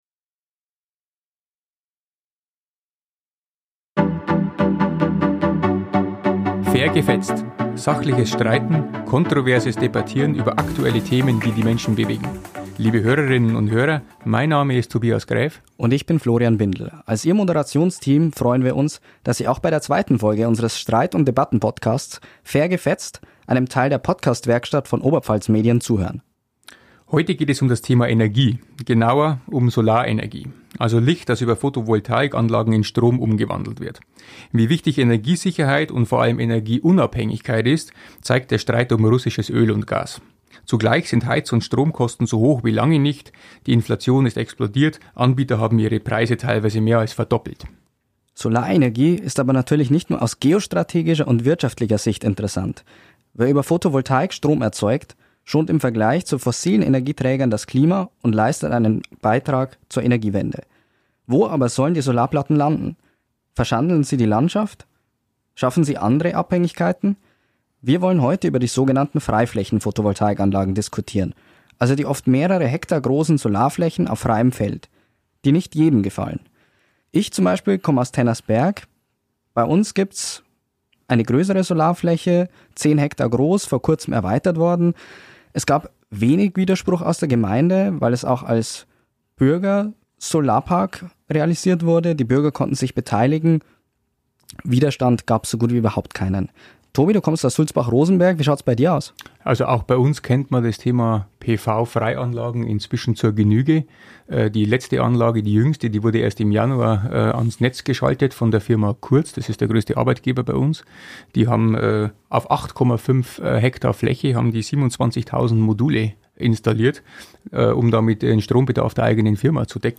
Ein Streitgespräch.